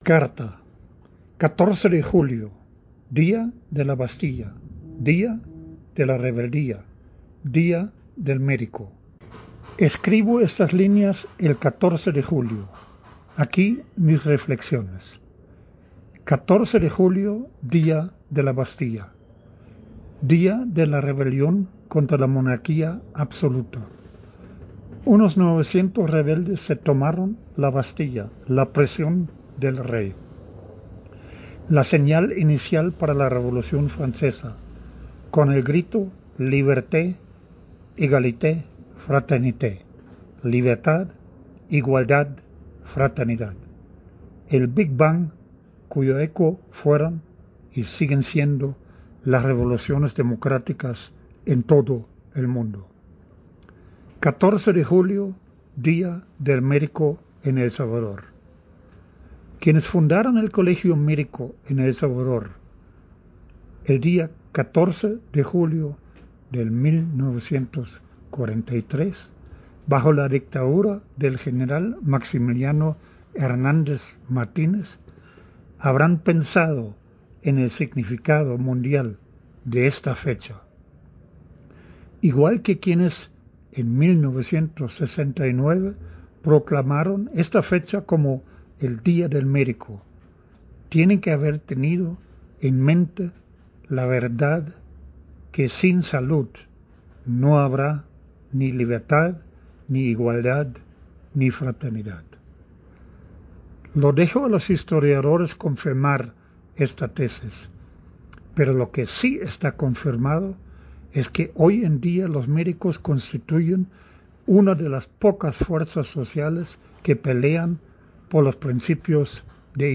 En la voz del autor: